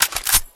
- Attach and detach weapon attachments has a sounds now.
action_attach_1.ogg